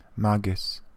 Ääntäminen
US : IPA : [mɔɹ] UK : IPA : [mɔː]